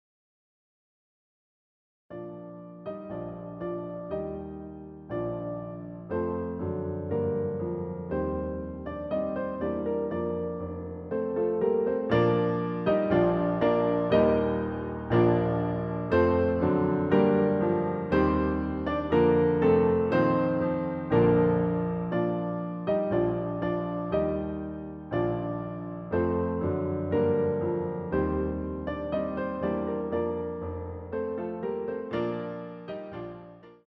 using the stereo sa1mpled sound of a Yamaha Grand Piano.